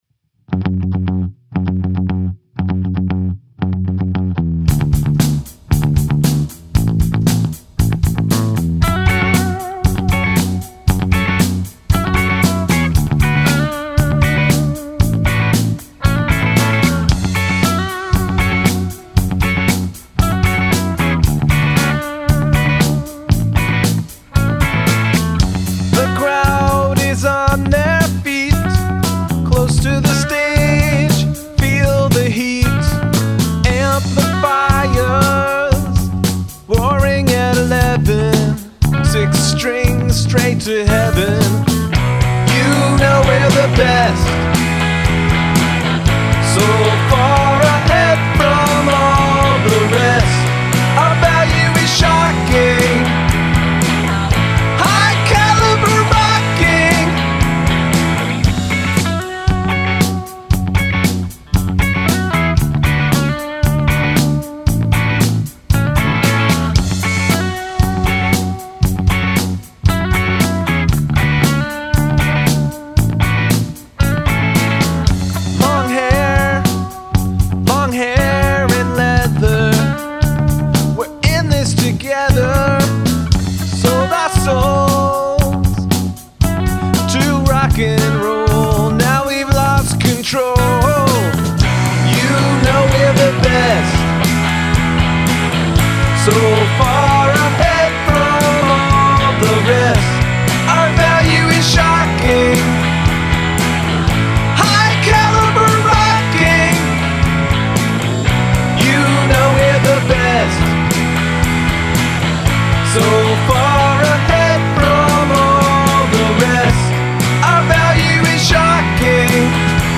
The bass is strong.